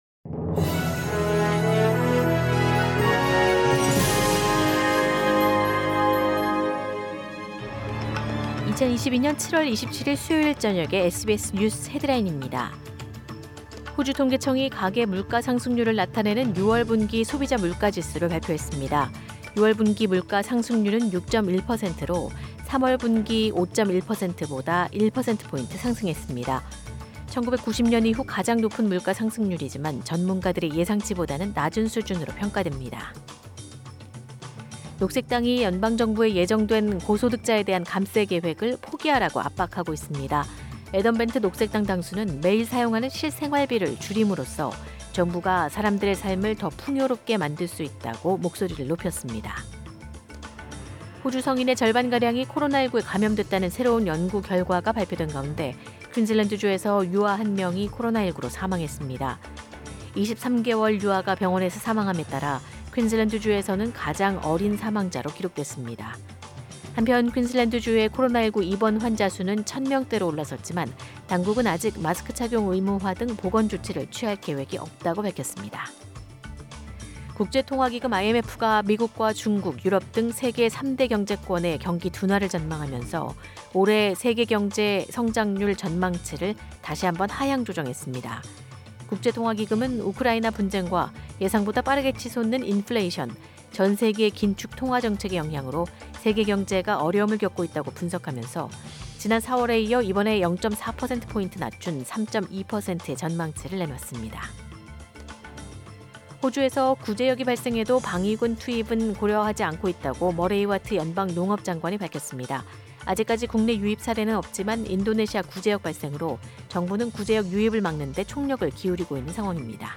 2022년 7월 27일 수요일 저녁 SBS 한국어 간추린 주요 뉴스입니다.